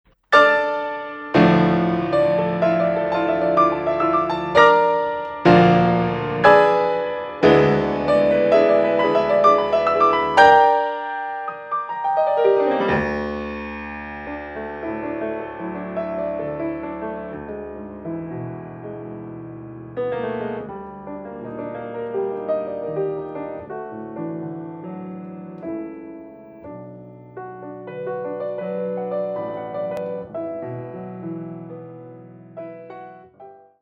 Geige
Klavier
Schlagwerker